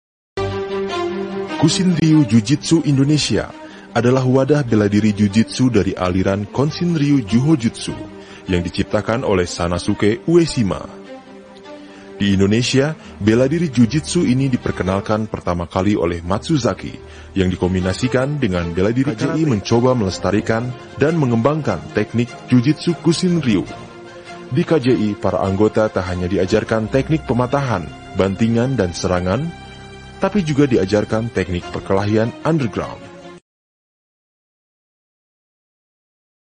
配音风格： 沉稳 稳重